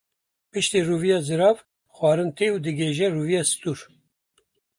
/zɪˈɾɑːv/